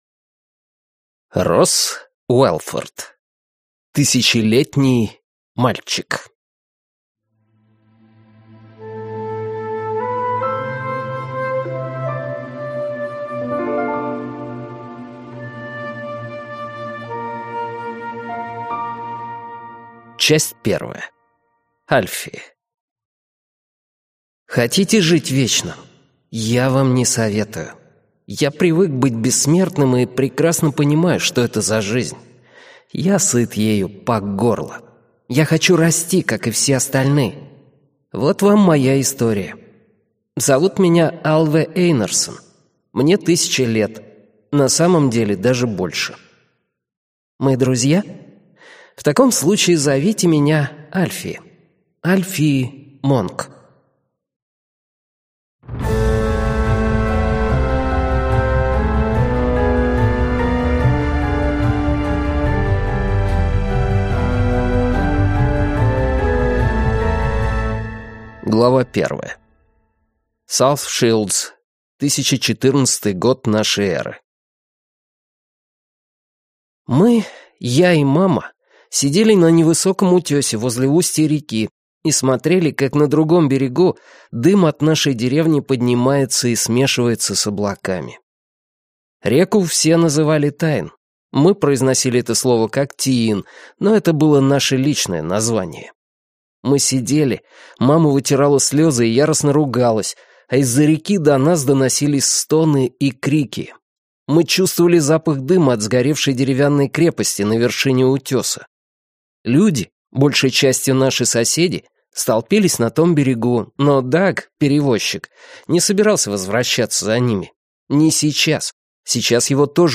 Аудиокнига Тысячелетний мальчик | Библиотека аудиокниг